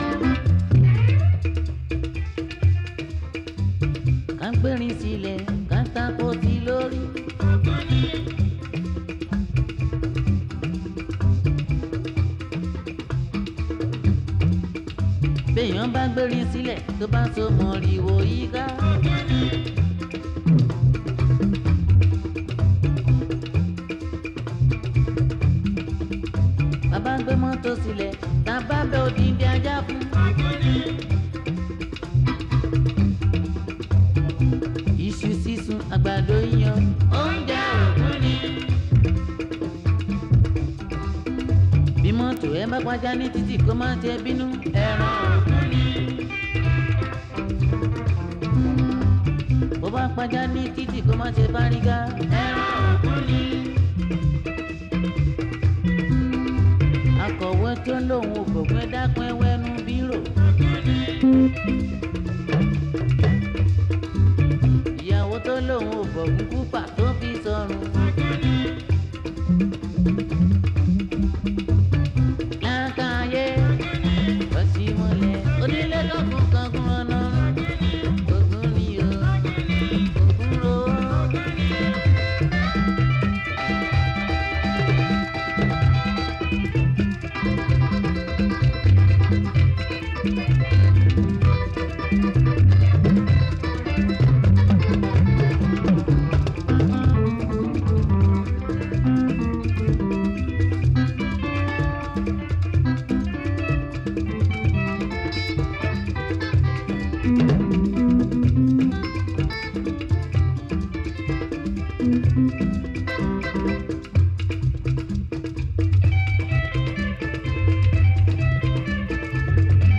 Gospel
is a Nigerian jùjú singer